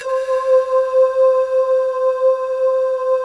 Index of /90_sSampleCDs/USB Soundscan vol.28 - Choir Acoustic & Synth [AKAI] 1CD/Partition D/03-PANKALE